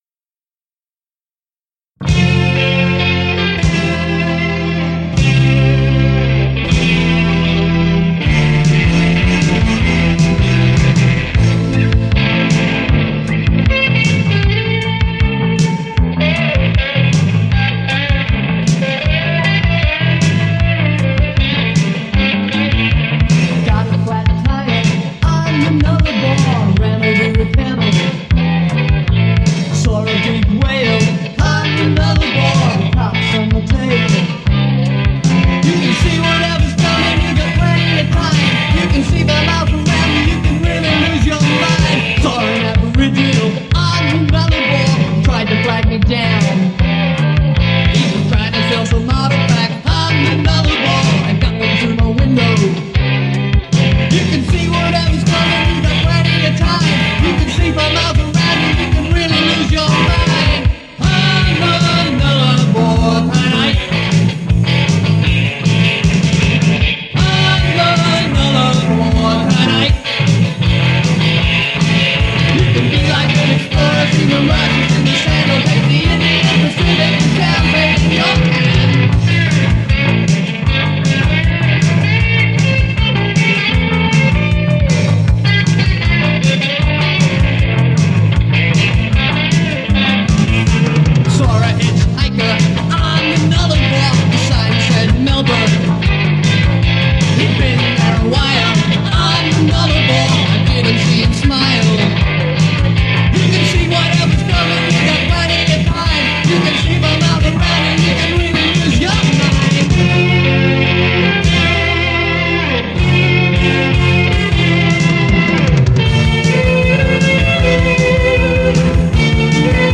lead vocals/bass/keyboards